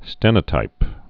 (stĕnə-tīp)